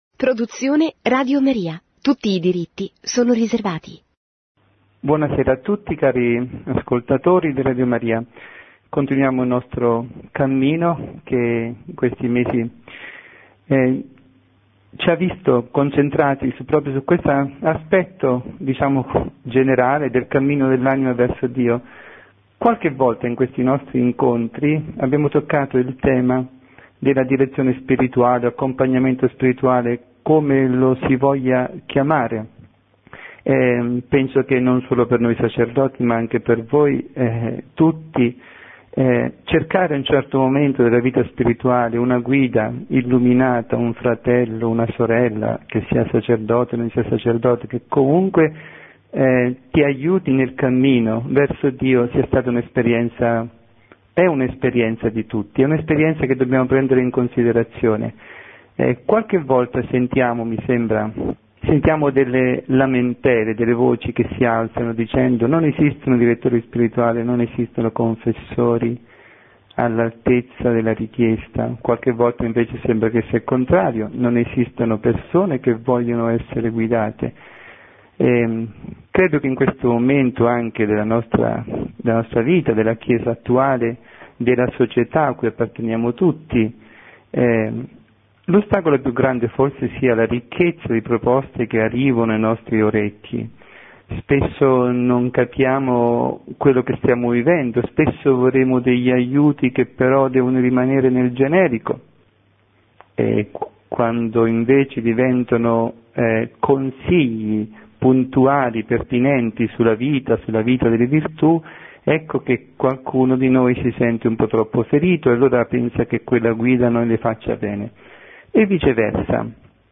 Catechesi
trasmessa in diretta su RadioMaria